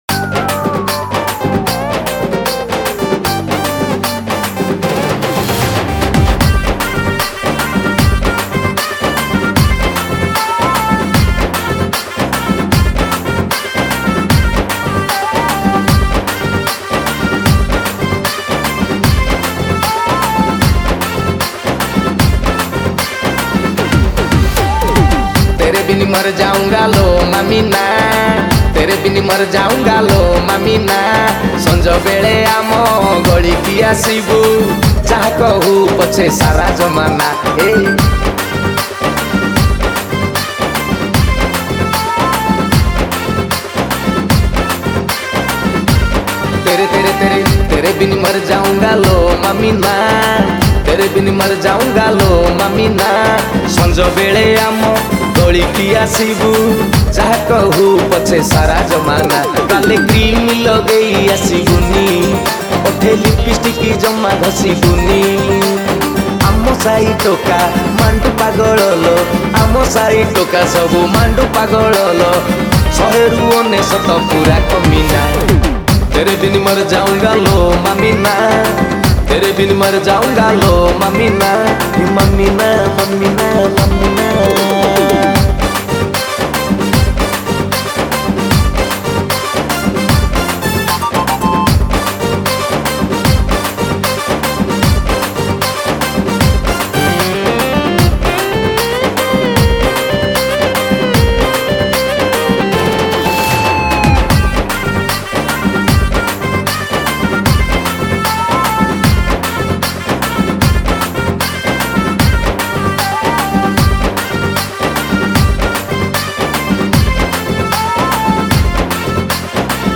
Song Type :Dance